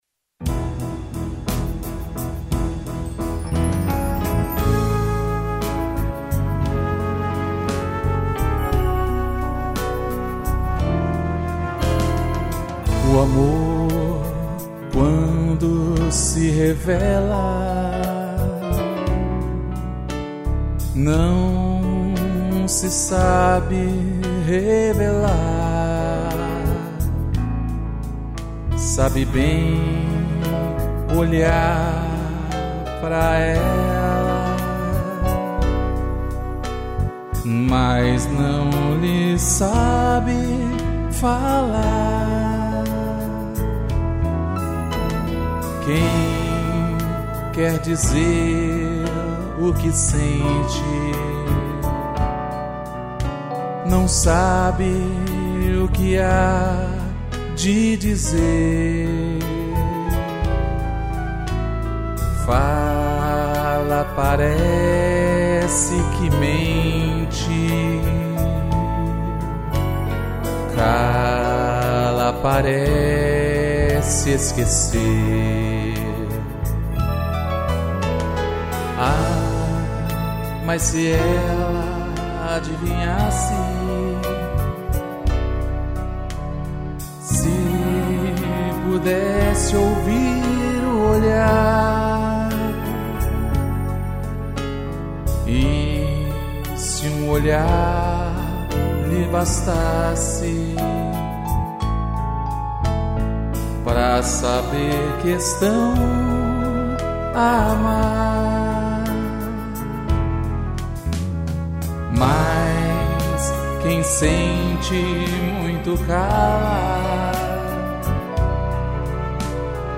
piano, violino, cello e strings